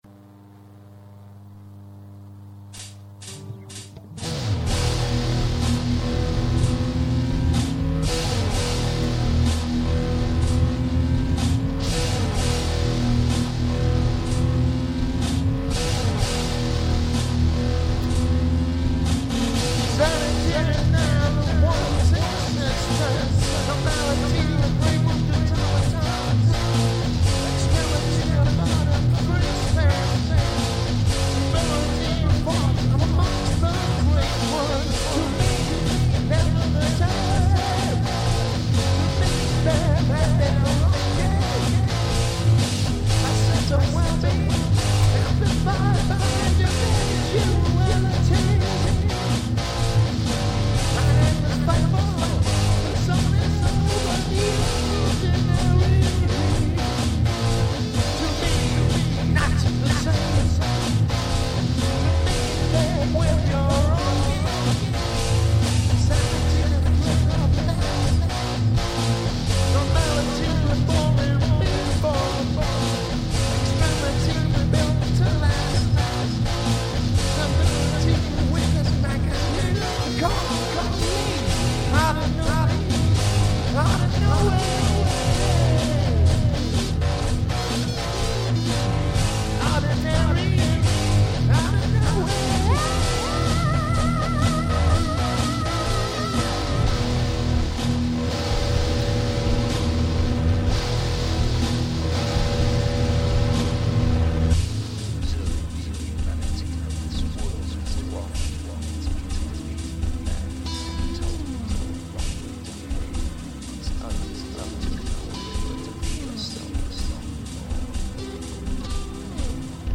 Again my dodgy in-flat vocals - this track
Bass, Guitar, Lead, Synth, Drums